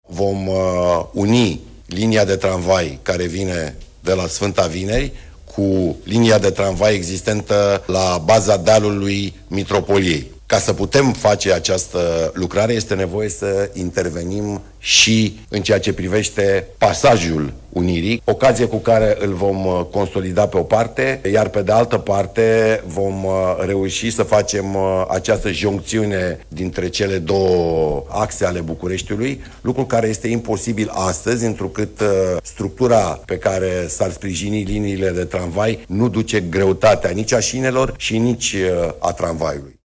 Pe 10 iunie încep lucrările la podul peste râul Dâmbovița, după ce Nicușor Dan a semnat, în ultima zi de mandat ca primar al Capitalei, autorizația de construire, singurul document de care mai era nevoie pentru începerea lucrărilor, a anunțat primarul sectorului 4, Daniel Băluță.